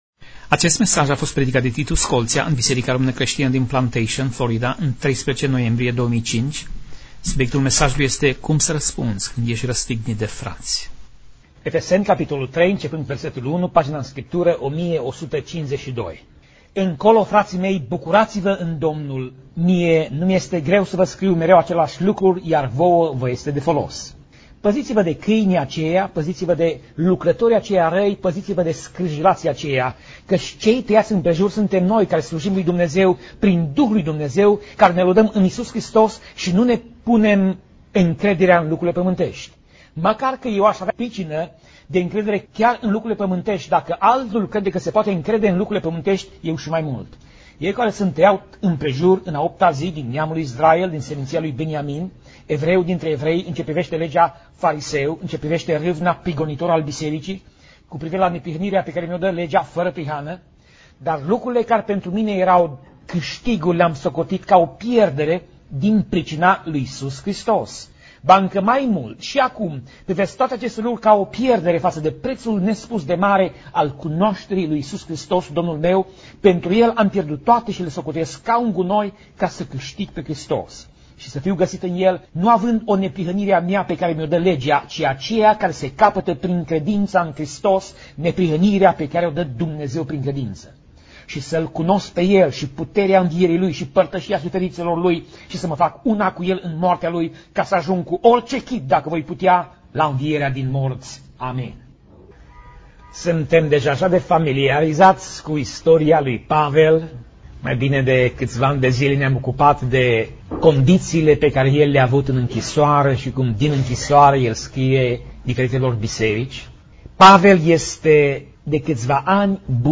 Pasaj Biblie: Filipeni 3:7 - Filipeni 3:11 Tip Mesaj: Predica